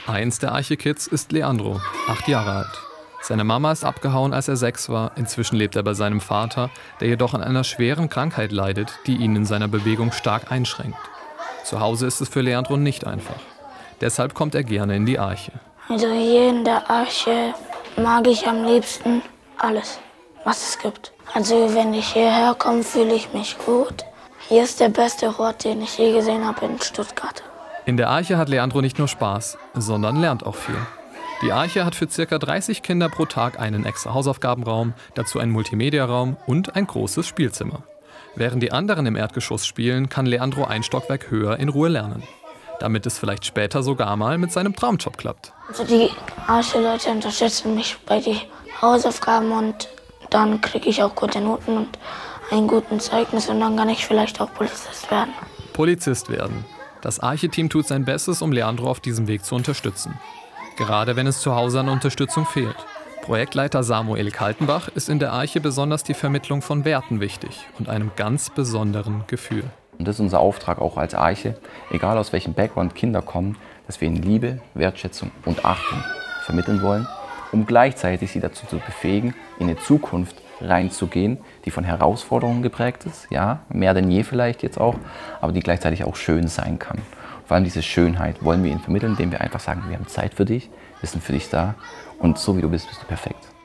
Eine Audio-Reportage aus Stuttgart gibt einen Einblick in die Arbeit der Einrichtung.